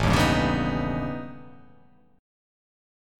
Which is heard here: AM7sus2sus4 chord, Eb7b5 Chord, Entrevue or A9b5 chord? A9b5 chord